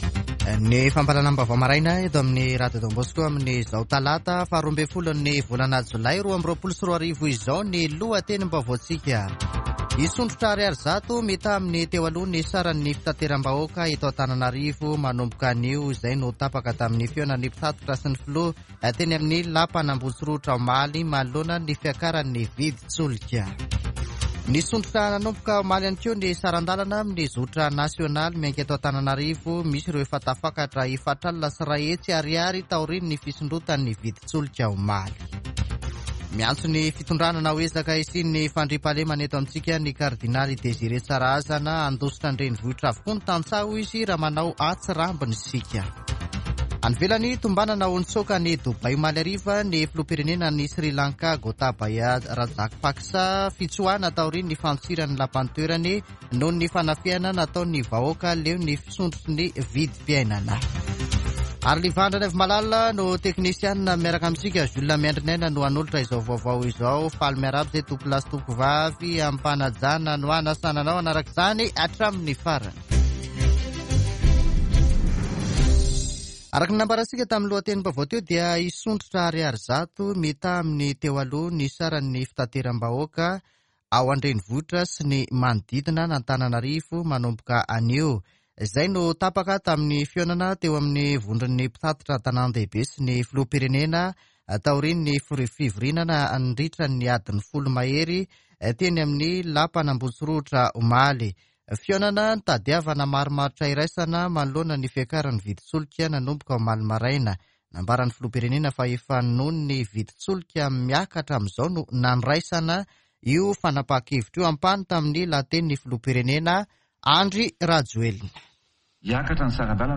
[Vaovao maraina] Talata 12 jolay 2022